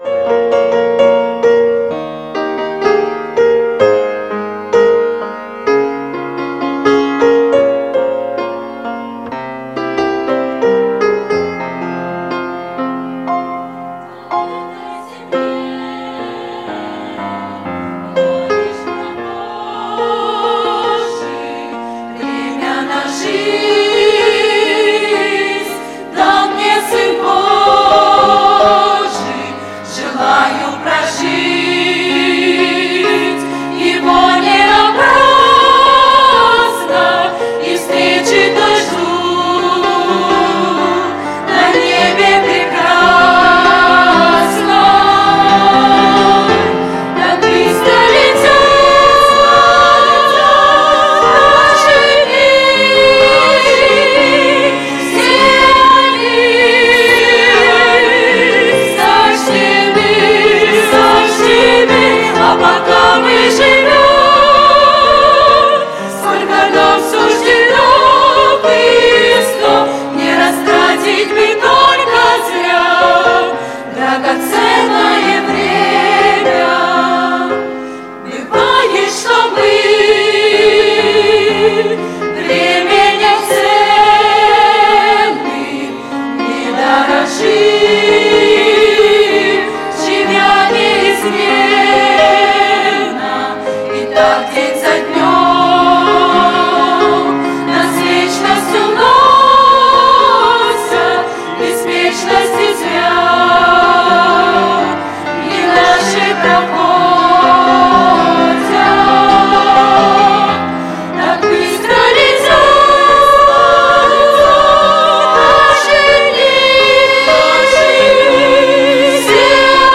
Конференция молодежи ОЦХВЕ Сибири 2019
На этой земле - Молодежь (г. Новосибирск) (Пение)[